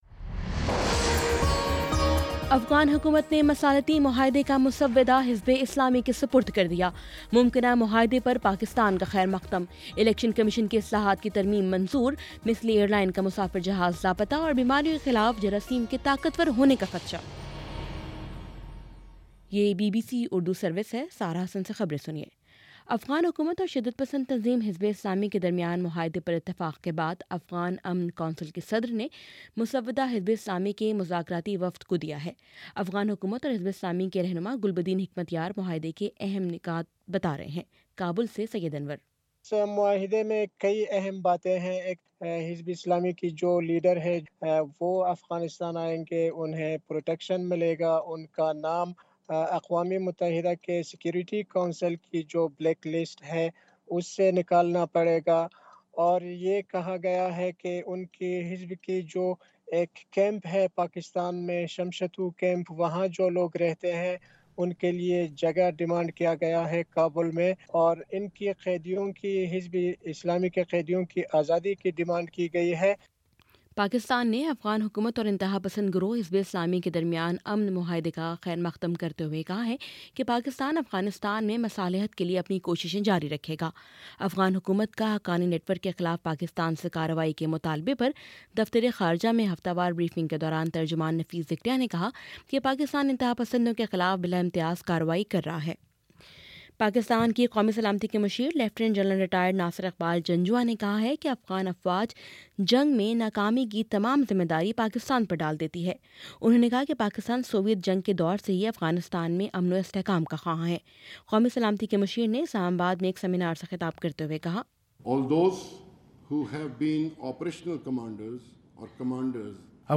مئی 19 : شام سات بجے کا نیوز بُلیٹن